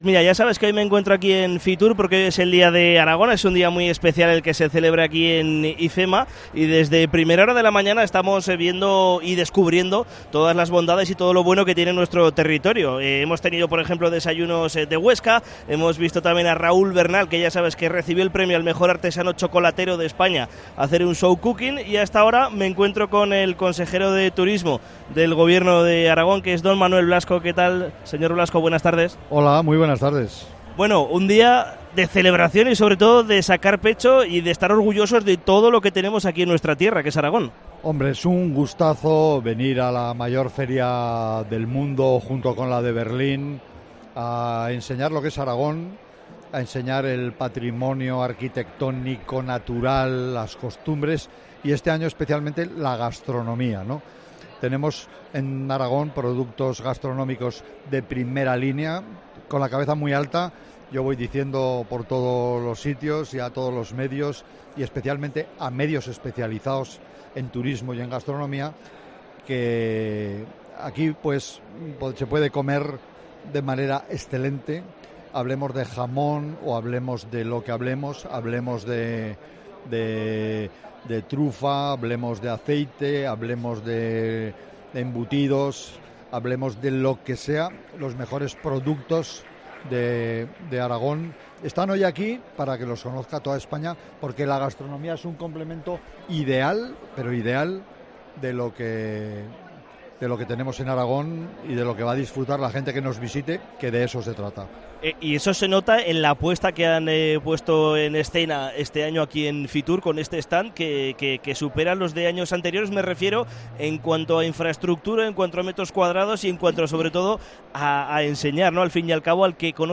Entrevista al consejero de Turismo del Gobierno de Aragón, Manuel Blasco, en FITUR 2024